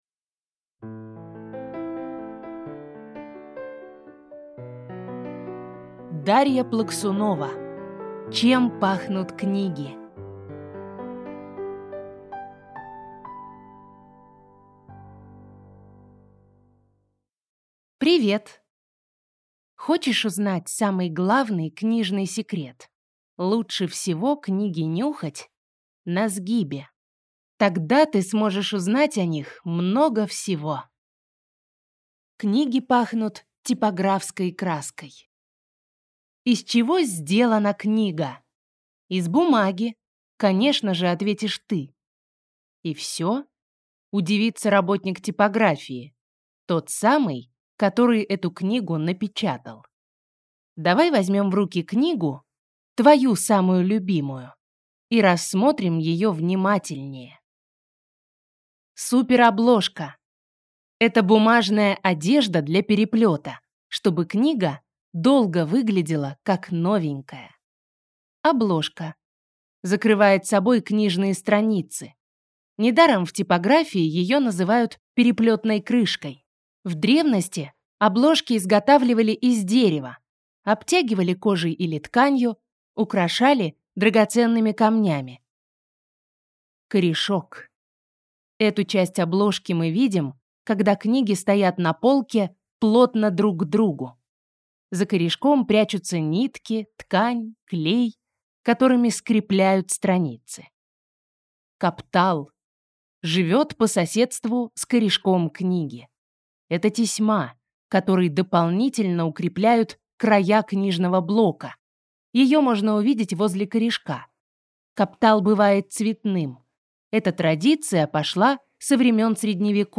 Аудиокнига Чем пахнут книги | Библиотека аудиокниг